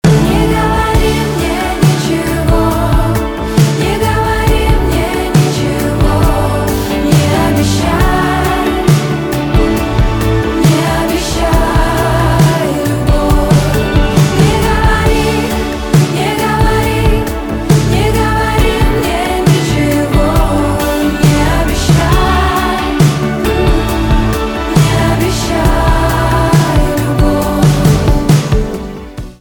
поп
гитара , барабаны
чувственные